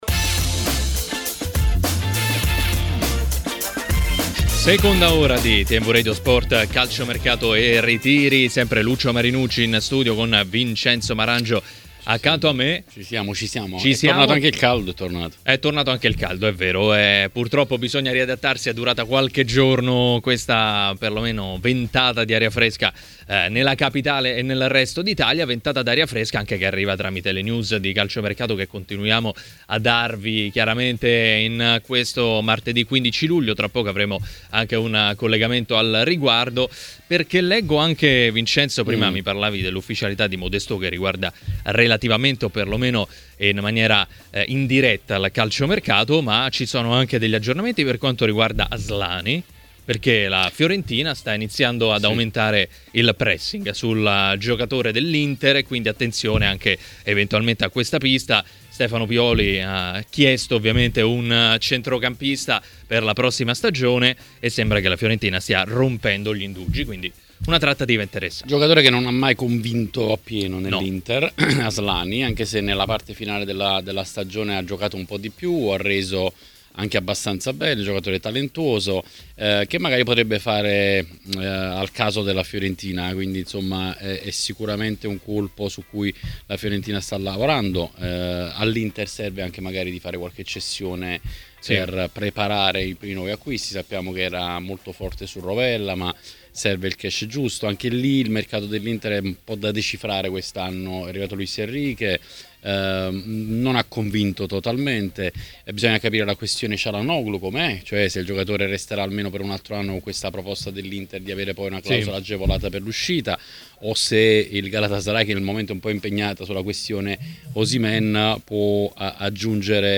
ha parlato dei temi del giorno a TMW Radio, durante Calciomercato e Ritiri.